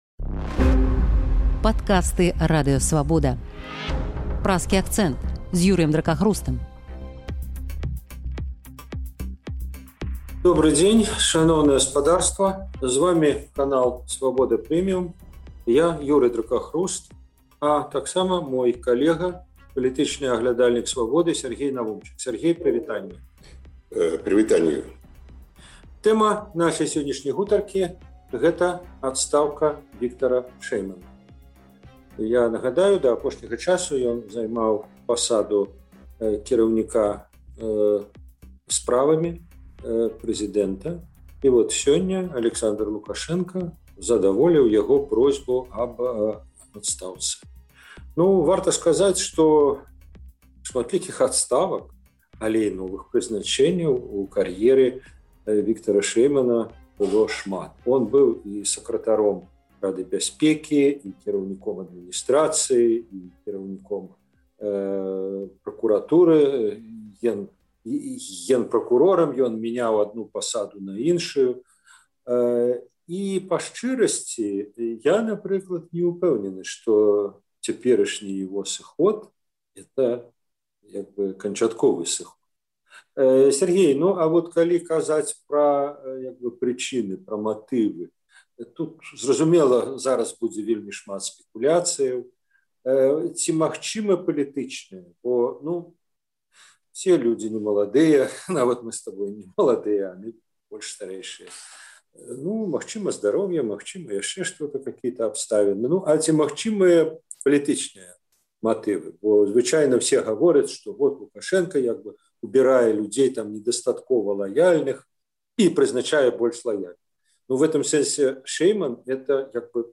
Гэтыя тэмы ў Праскім акцэнце абмяркоўваюць